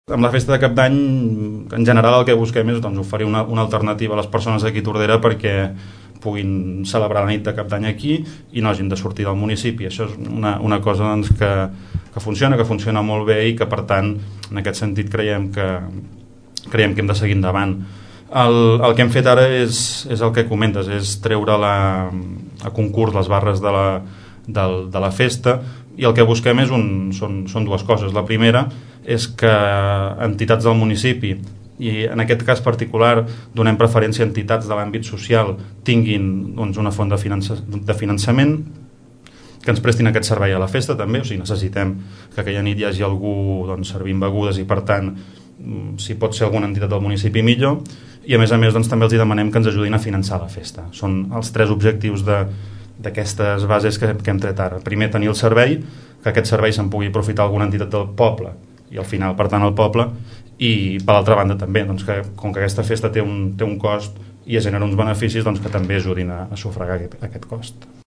El regidor de joventut, Marc Unió parla d’una festa molt consolidada al municipi.